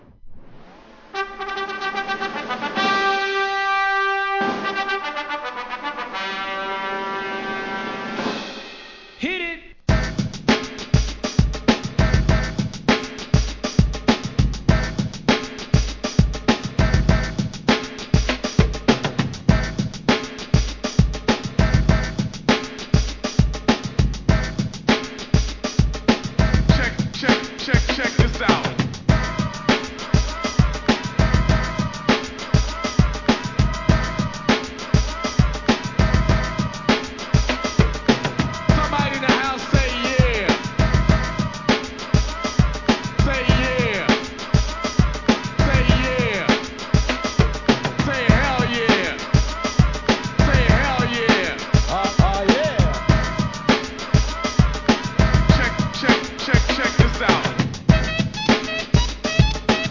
HIP HOP〜エレクトロ・ブレイクビーツEP